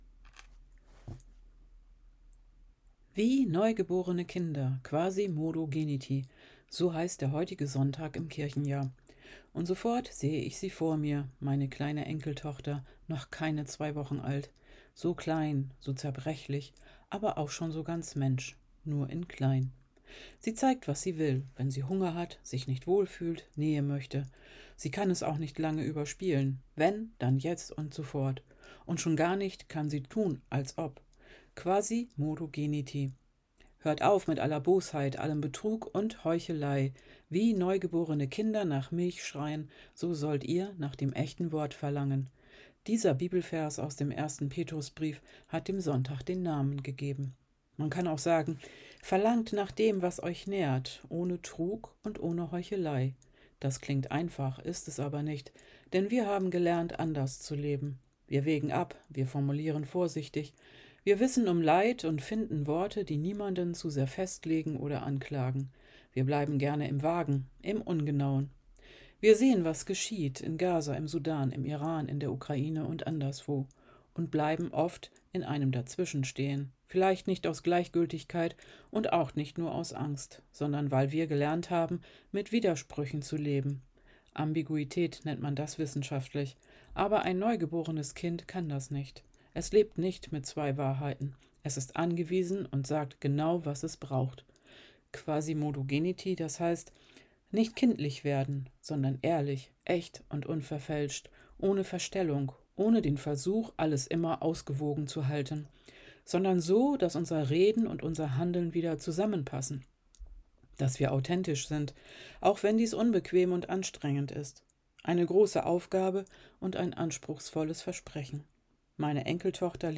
Quasimodogeniti ~ Telefon-Andachten des ev.-luth. Kirchenkreises Lüchow-Dannenberg Podcast